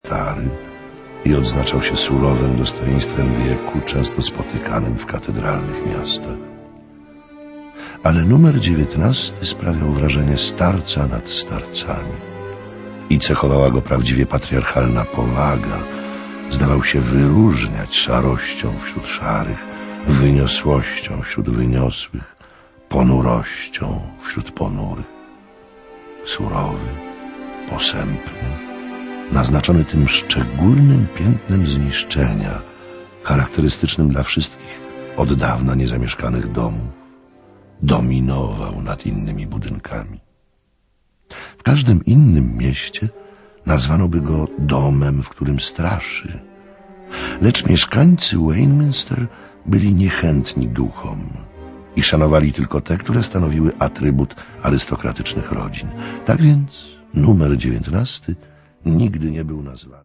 Sluchowisko na podstawie opowiadania o tym samym tytule.